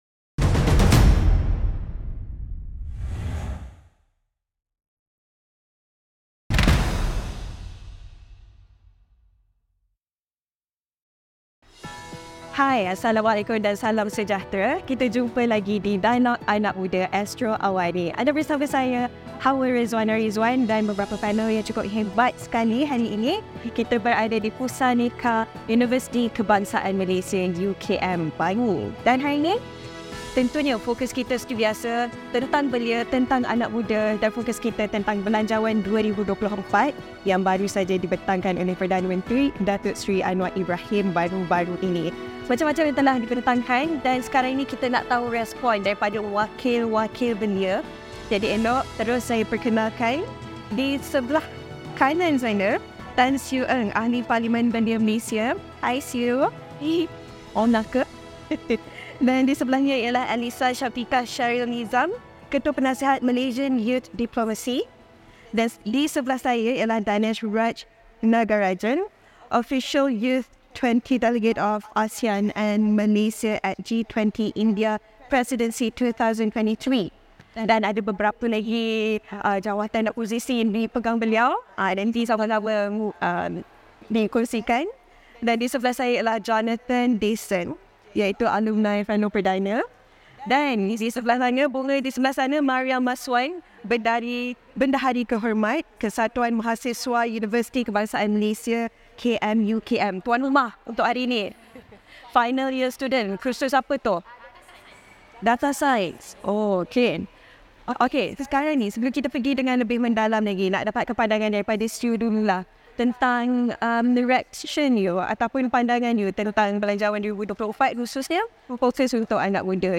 Analisis pasca pembentangan Belanjawan 2024, apa pula pandangan anak muda? Diskusi